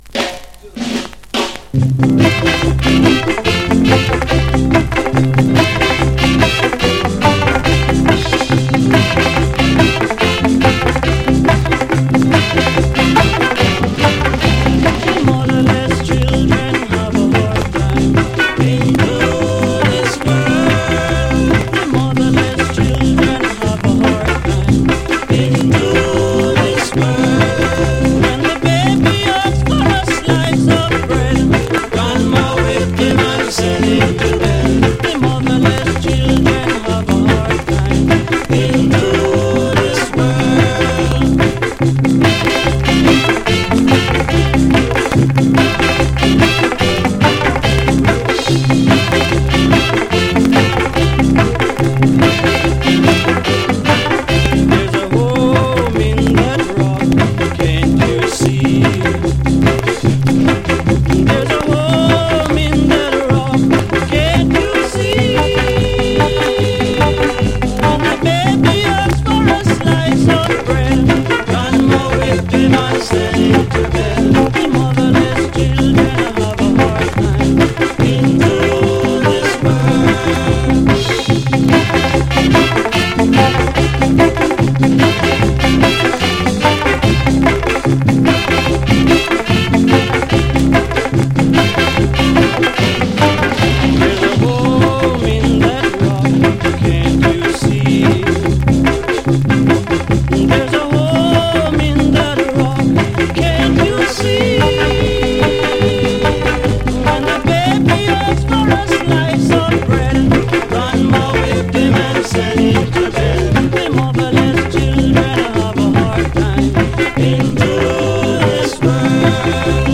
Apart from some miking issues
Early Reggae